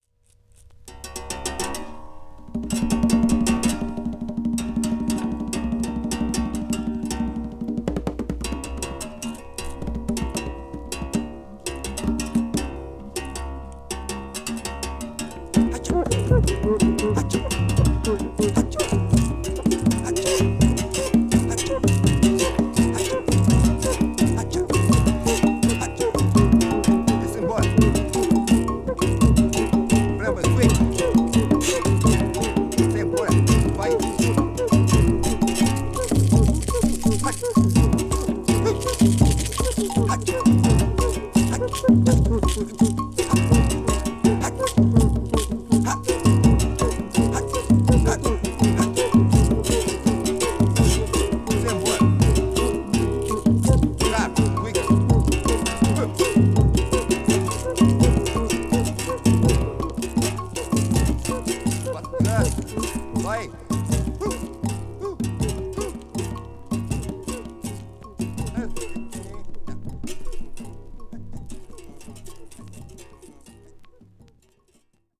the primitive charm of berimbau and chants on A3